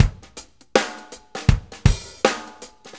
Shuffle Loop 23-01.wav